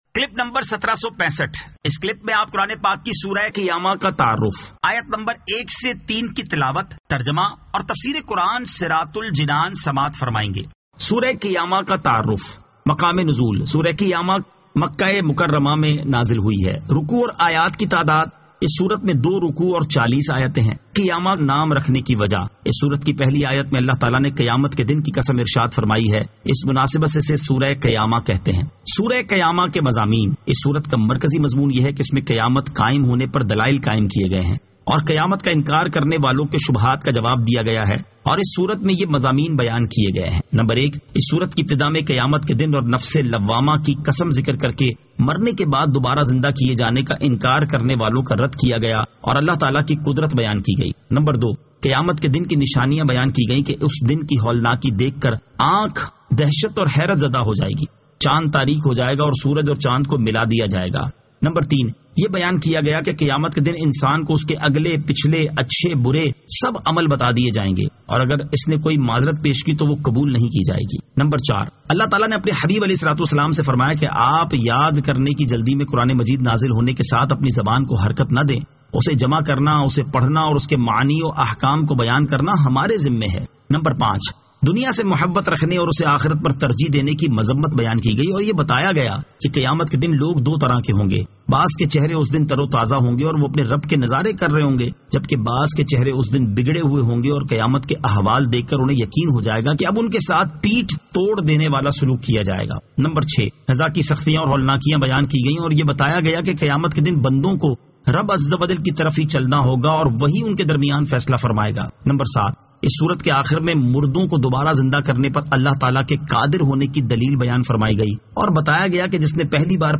Surah Al-Qiyamah 01 To 03 Tilawat , Tarjama , Tafseer